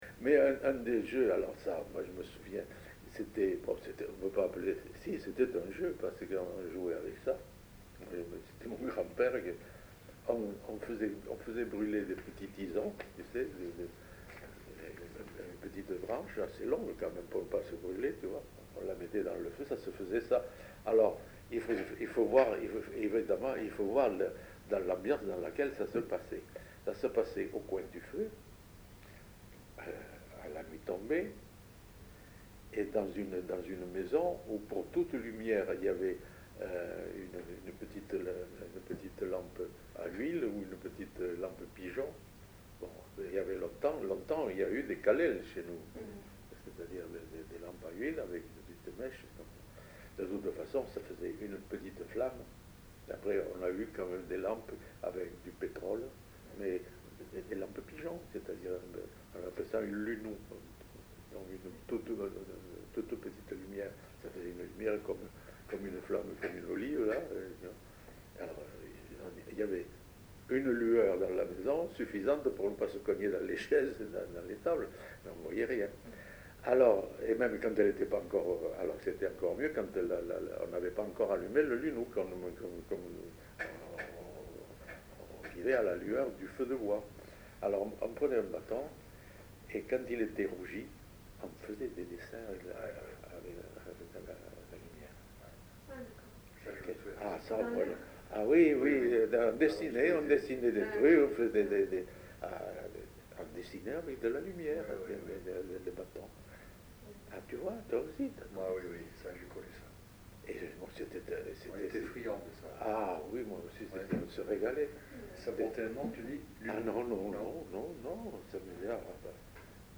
Lieu : Saint-Sauveur
Genre : témoignage thématique